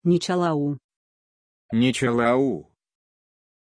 Pronunția numelui Nicolau
pronunciation-nicolau-ru.mp3